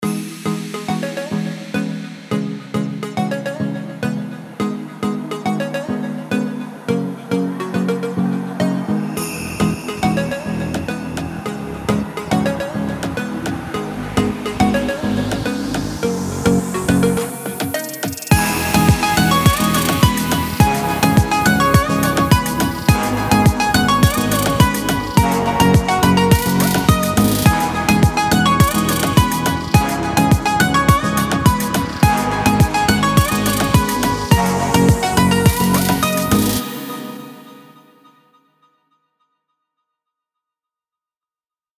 打开一瓶啤酒，这包啤酒将把您的音乐带入一个全新的热带氛围。
-包含完美制作的鼓，FX，合成音，鼓循环，人声和鼓音的样本包